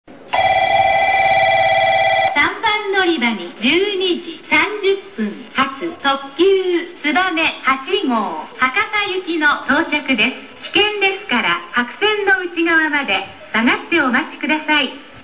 ３番のりば 接近放送 特急つばめ・博多 (75KB/15秒)
九州標準放送です。